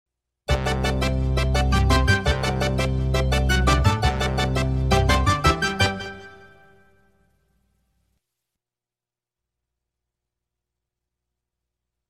Звуки логотипа